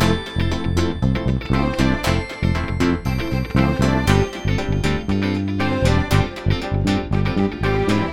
28 Backing PT4.wav